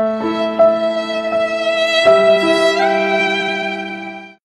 Sad Violin
Sad-Violin-the-Meme-One-Sound-Effects-Download-.mp3